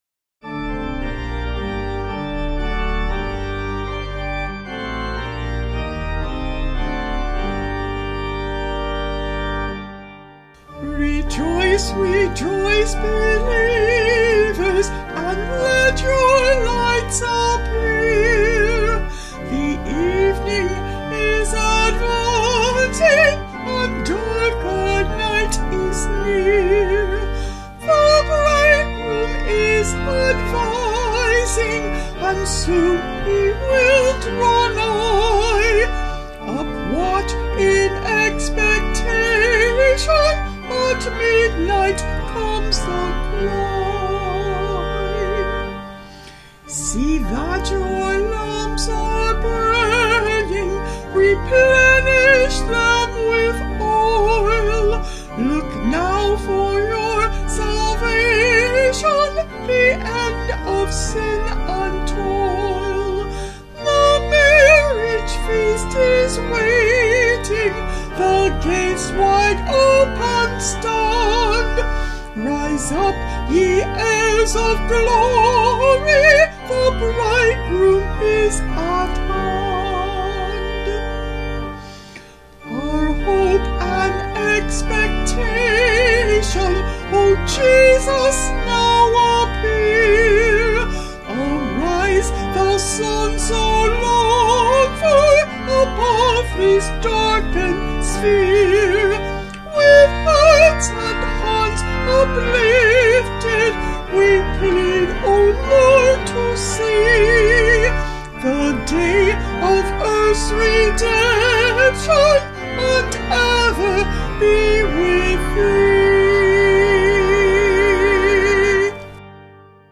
Vocals and Organ   211.7kb Sung Lyrics